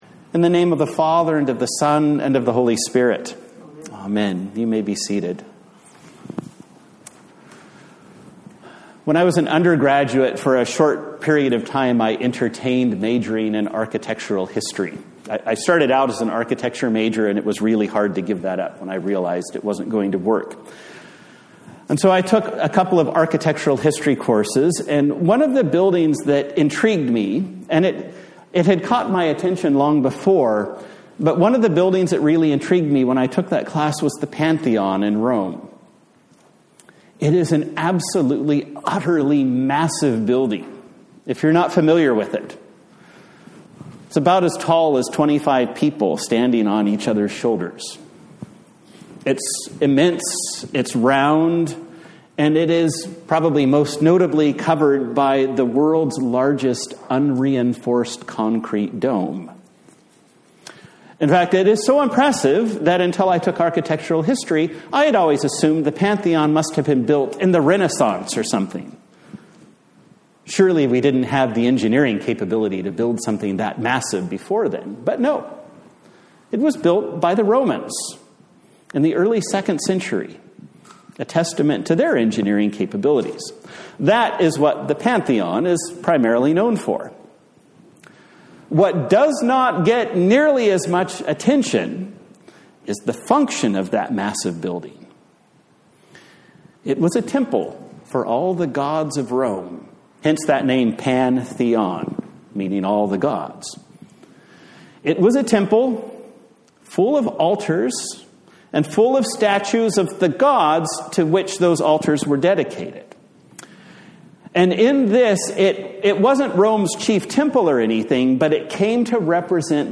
A Sermon for Palm Sunday